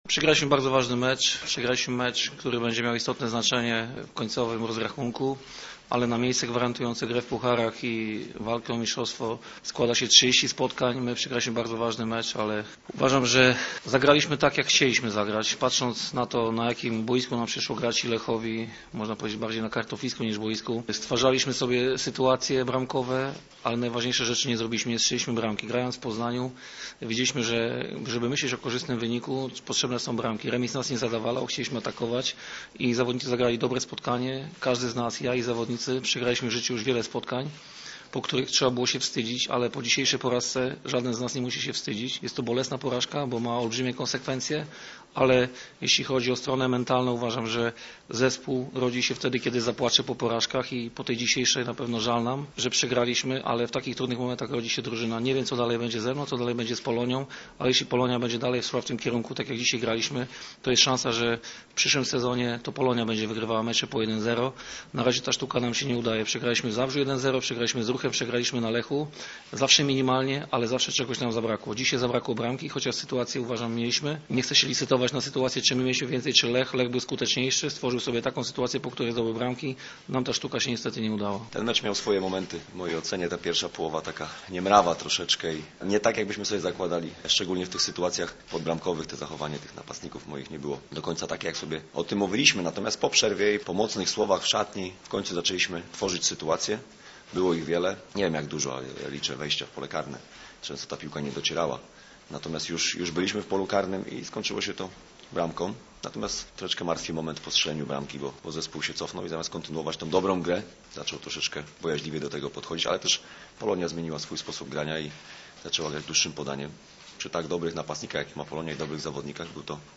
nzs6ddxoy73ed27_lech_polonia_rozmowy_pomeczowe.mp3